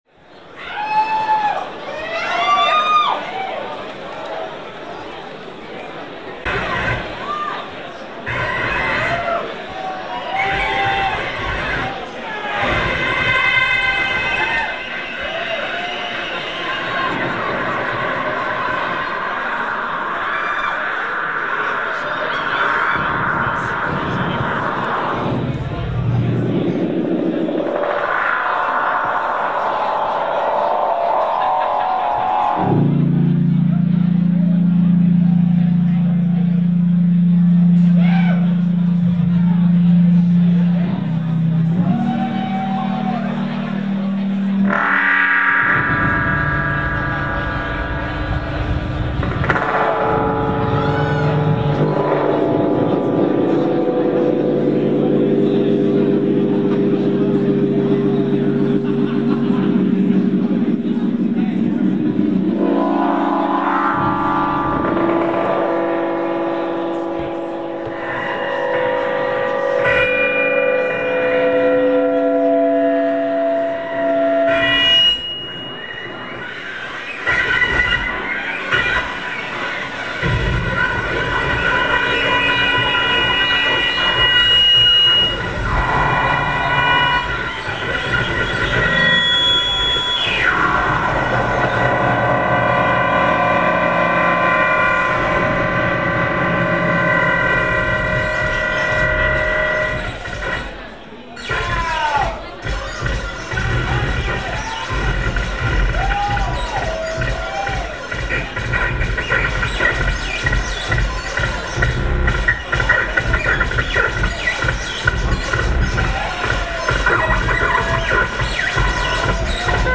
location San Franscisco, USA